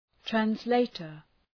{trænz’leıtər, træns’leıtər} (Ουσιαστικό) ● μεταφράστης